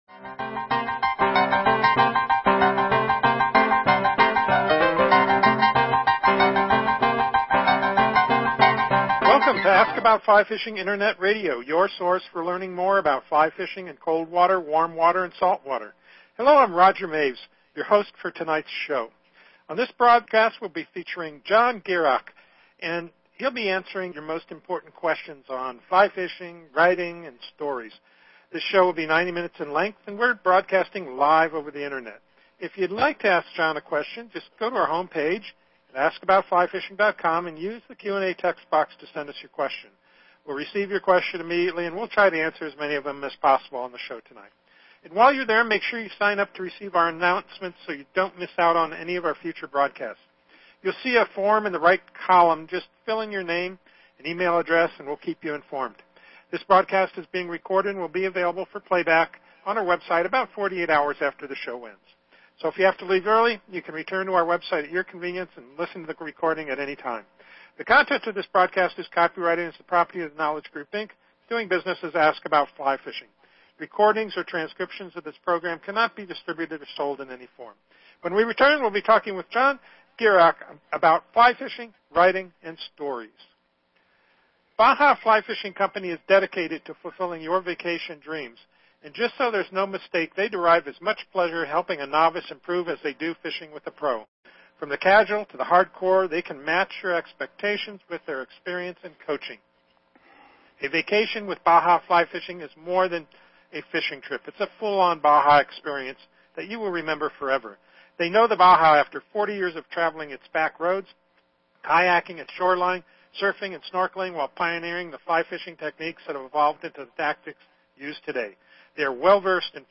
Join us for a chat with John about his writing, inspiration and of course fly fishing.